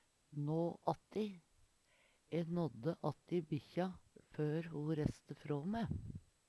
nå atti - Numedalsmål (en-US)